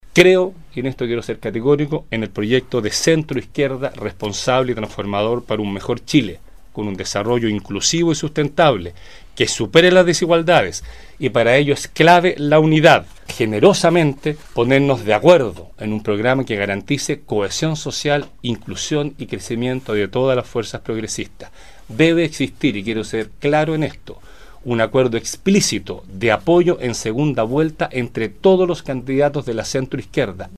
El legislador, hasta ahora representante del Distrito 57, dio a conocer su determinación este lunes en conferencia de prensa en Puerto Montt, reconociendo que ya había informado de ello a la presidenta de su partido, la Democracia Cristiana, senadora Carolina Goic.